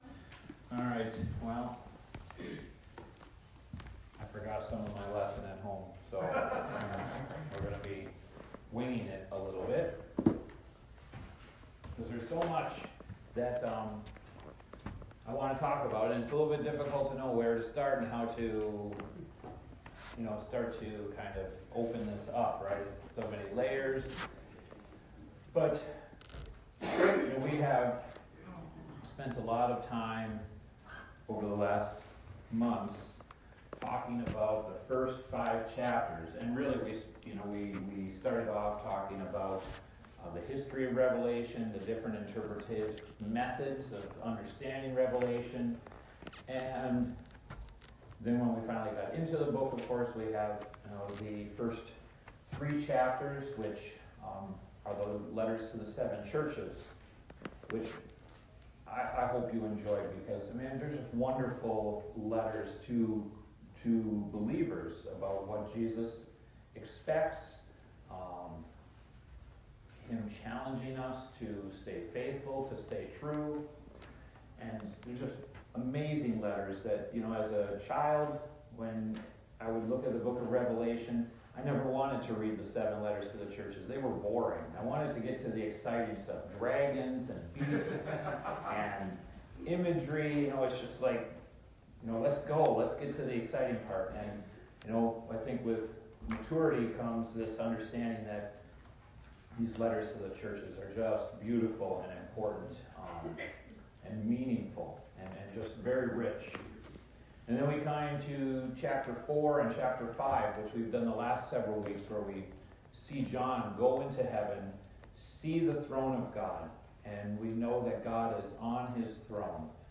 Adult Sunday School – Revelation 6-18 Overview